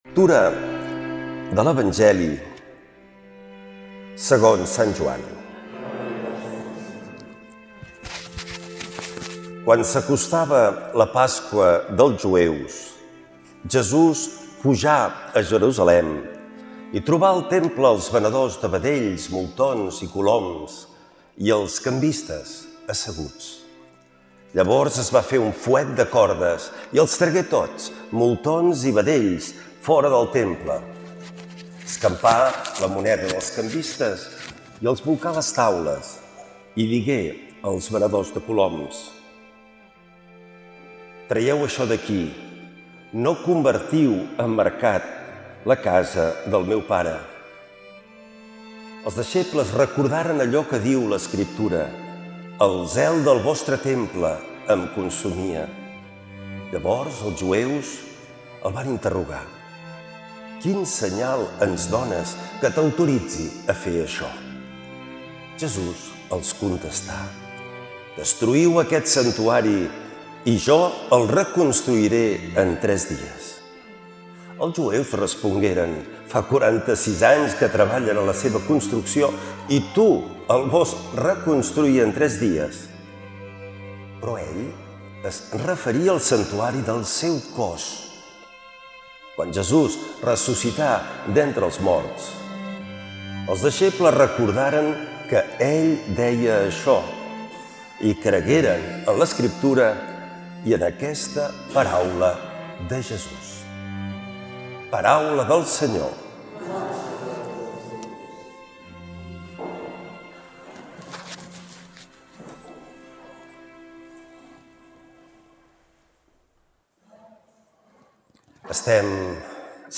L’Evangeli i el comentari de diumenge 09 de novembre del 2025.
Lectura de l’evangeli segons sant Joan